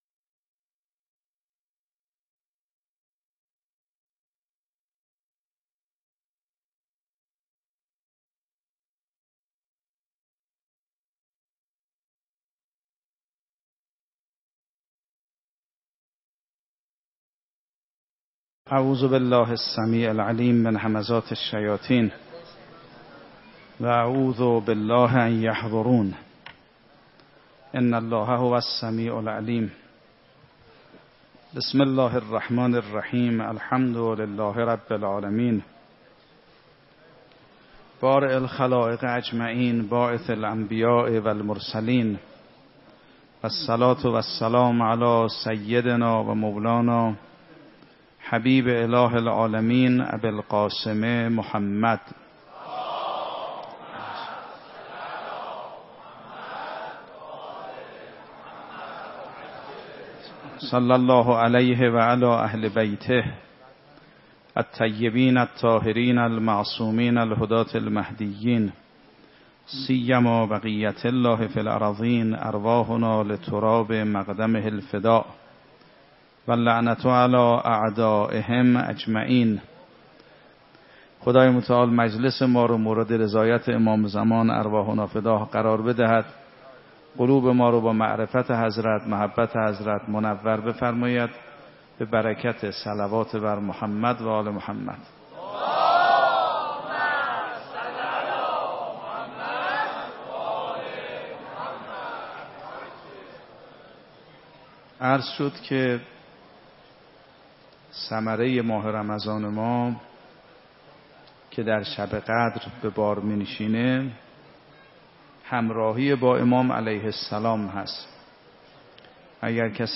شب هفتم رمضان 96 - مسجد ارک - سخنرانی